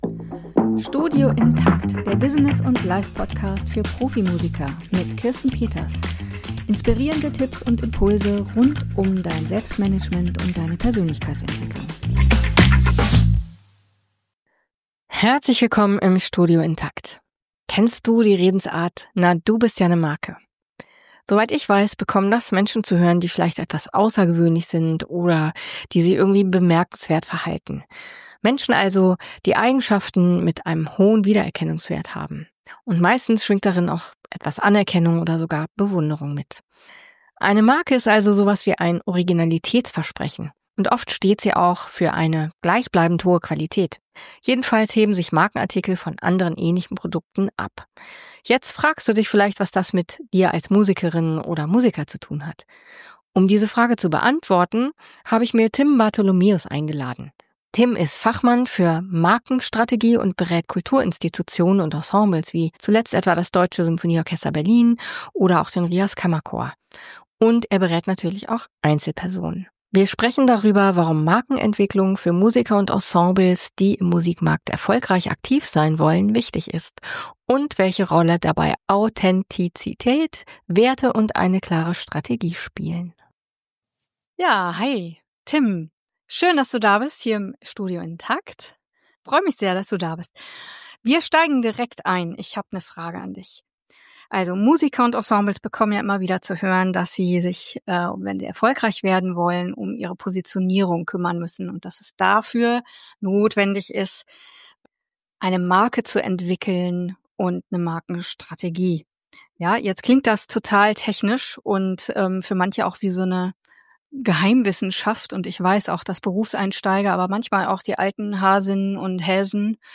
Ein erhellendes Gespräch über Selbst- und Fremdbild, Werte, Sichtbarkeit, Strategie und das „Warum“ hinter der dem, was wir tun.